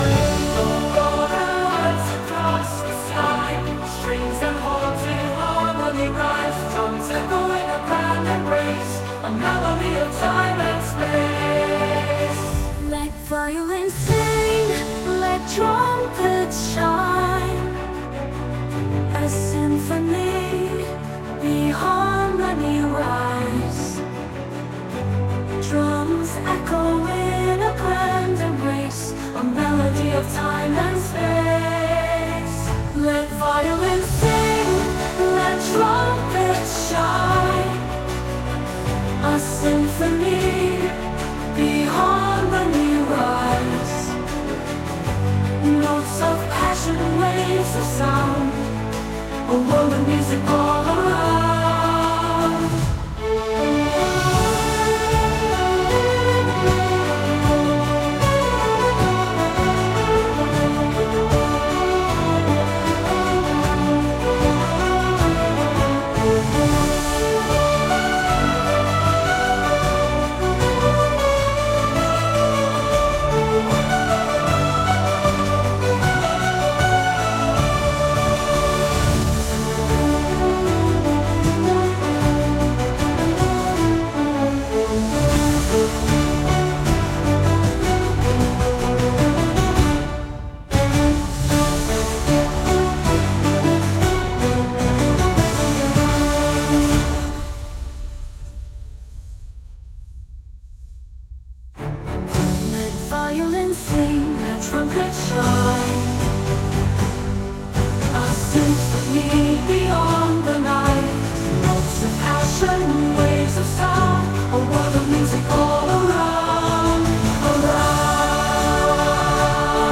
8. How to Create a Full Orchestra Song on Suno?
Suno AI can generate orchestral music based on lyrics and descriptions.